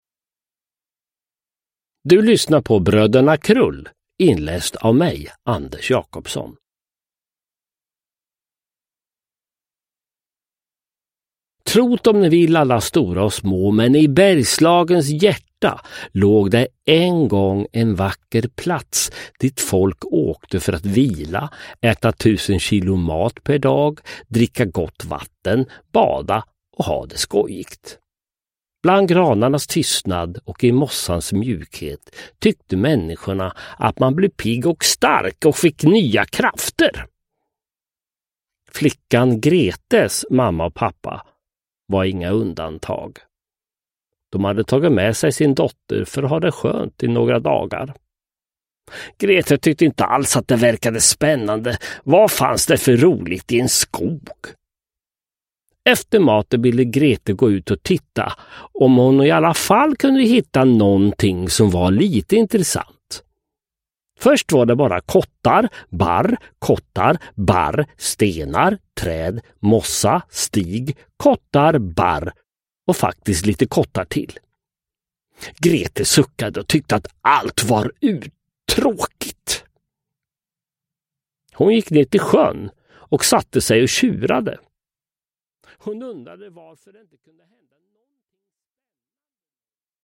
Bröderna Krull – Ljudbok – Laddas ner
Uppläsare: Sören Olsson, Anders Jacobsson